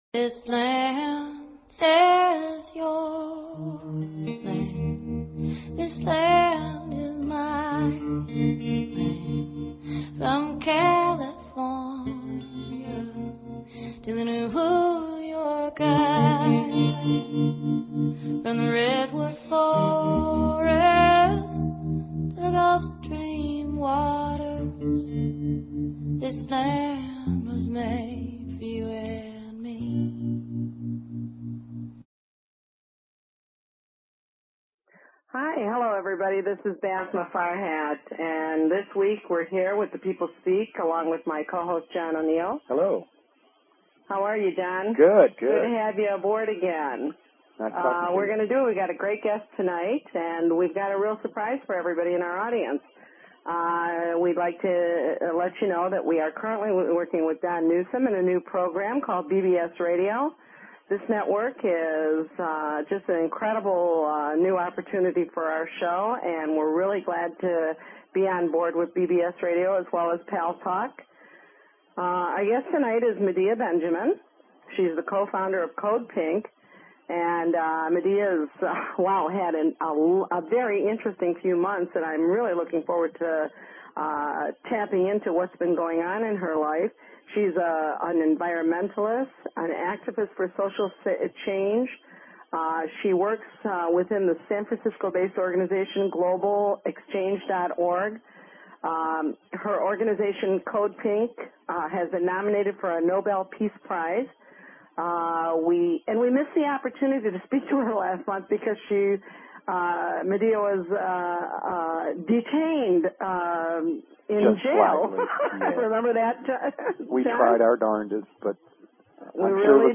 Guest, Medea Benjamin